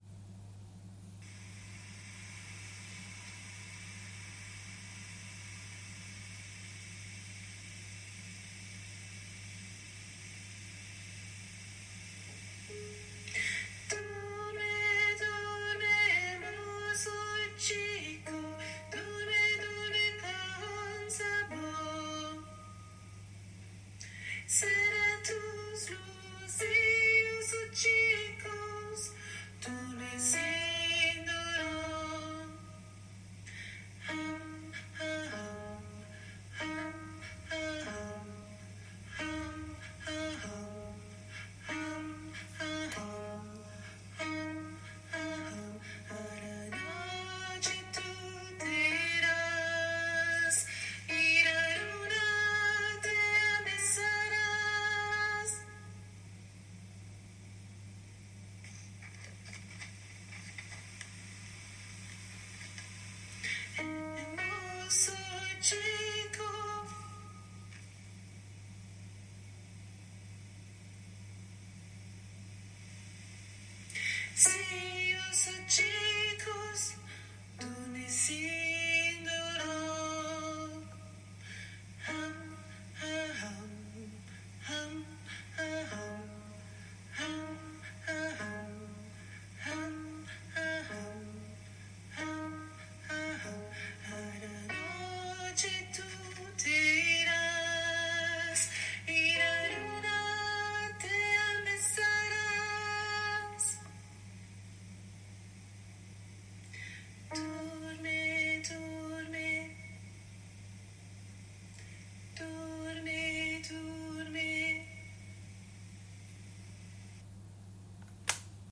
- berceuses juive séfarade
MP3 versions chantées
Soprano